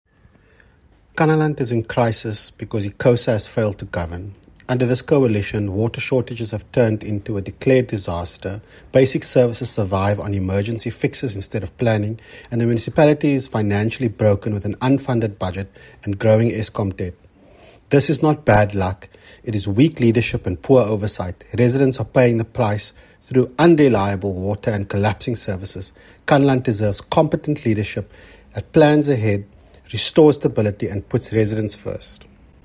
soundbite by Gillion Bosman